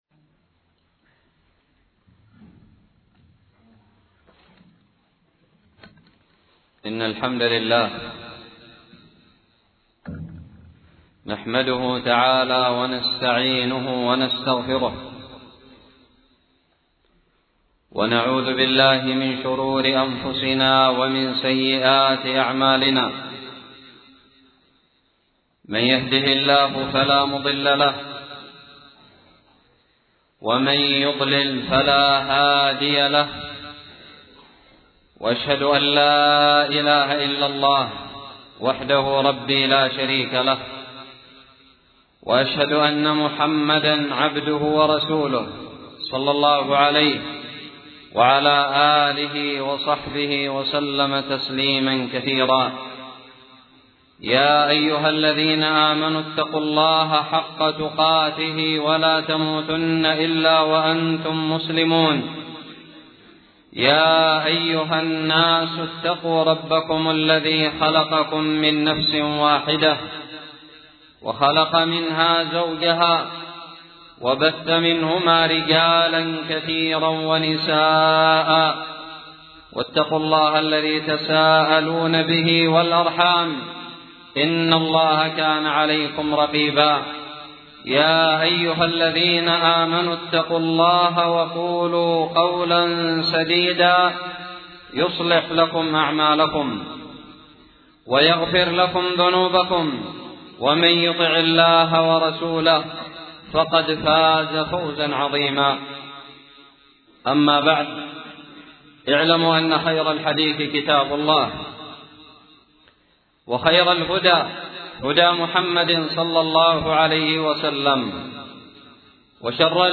خطب الجمعة
ألقيت بدار الحديث السلفية للعلوم الشرعية بالضالع في عام 1438هــ